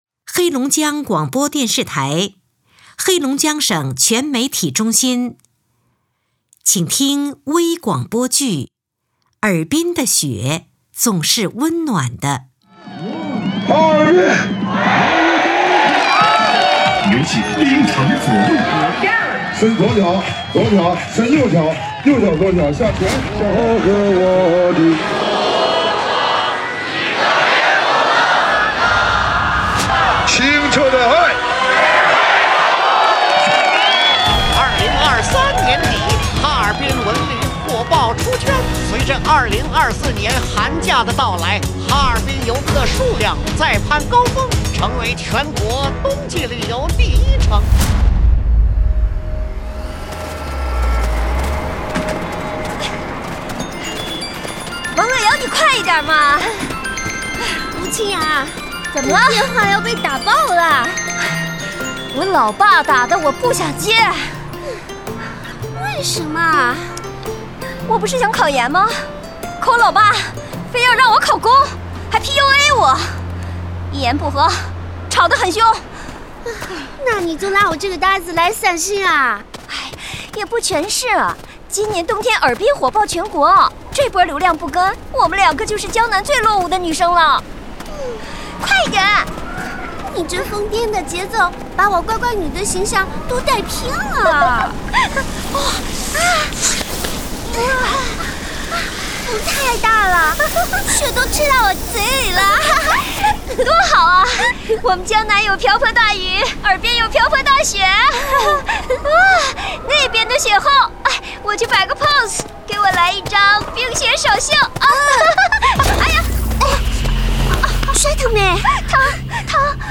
中国广播剧场 首页 > 微广播剧 > 尔滨的雪总是温暖的